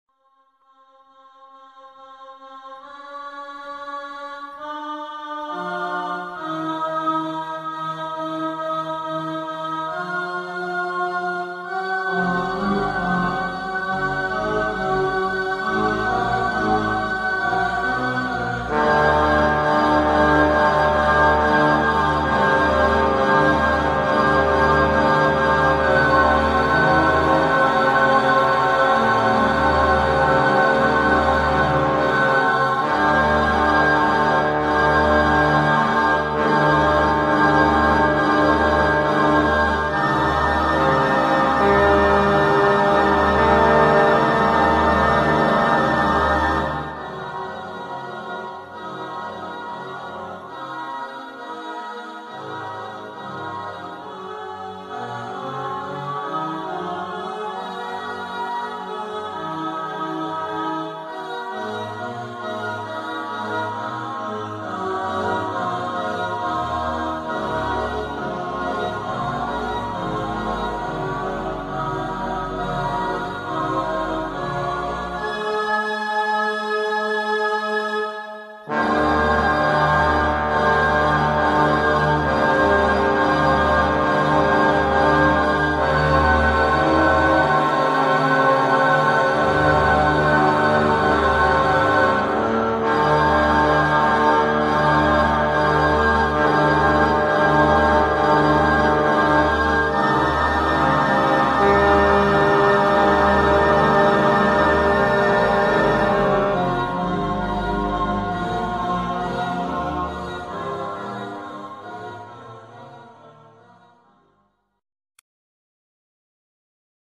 For two choirs and three trombones.
Excerpt from 1st movement (synthesised realisation)